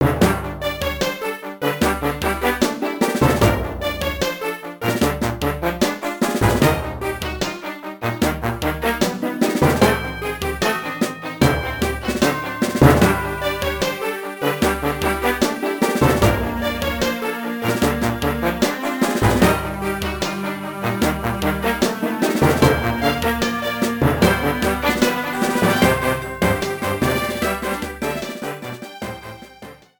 Ripped with Nitro Studio 2
Cropped to 30 seconds, fade-out added